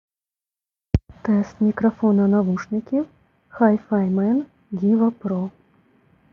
Модуль оснащений мікрофоном, який дає змогу відповідати на телефонні дзвінки, але якість звуку низька і приглушена, а передача відбувається з невеликою затримкою.